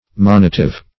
Monitive \Mon"i*tive\, a. Conveying admonition; admonitory.